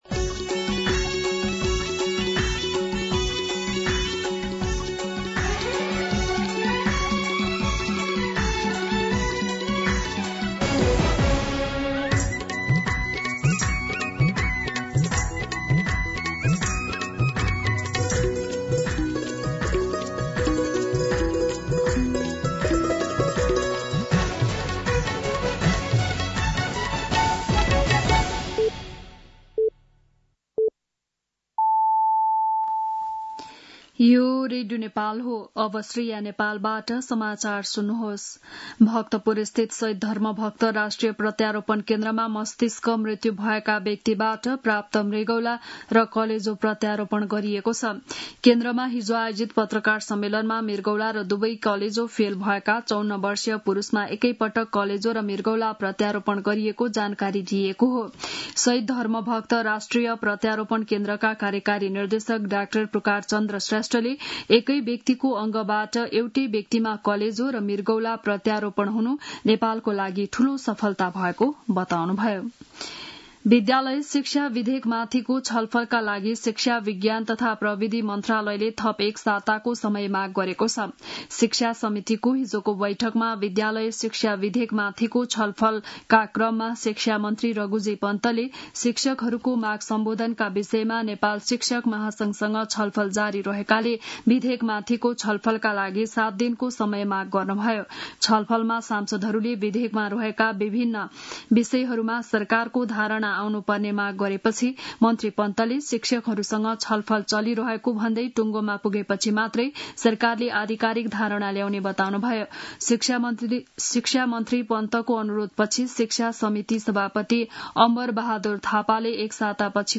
बिहान ११ बजेको नेपाली समाचार : १२ जेठ , २०८२